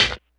hihat03.wav